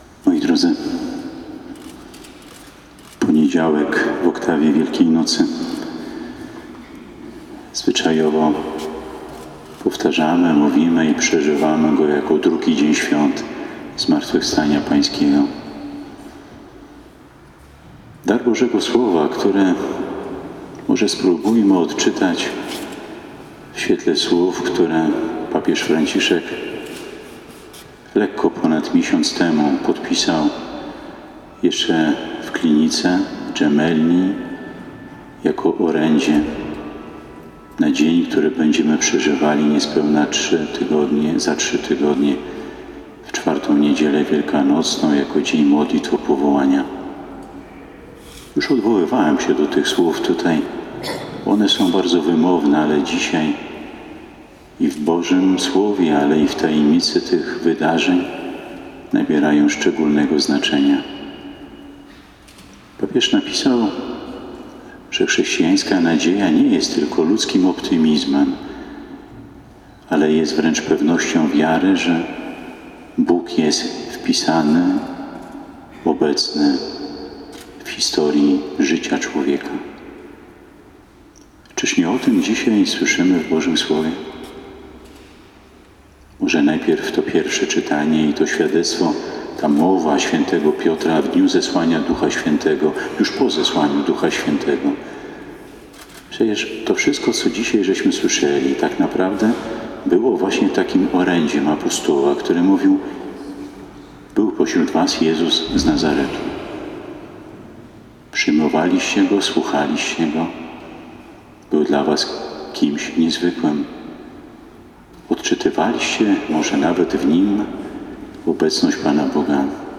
W radomskiej katedrze modlono się w dniu śmierci papieża Franciszka.
Bp Marek Solarczyk, homilia:
bp_m_solarczyk_homilia.mp3